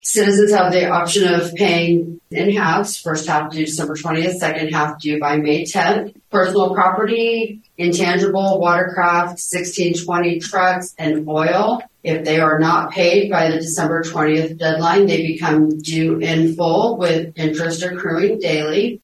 Taxes are due soon, and Riley County Treasurer Shiloh Heger advised the community during Monday’s Riley County Commission meeting.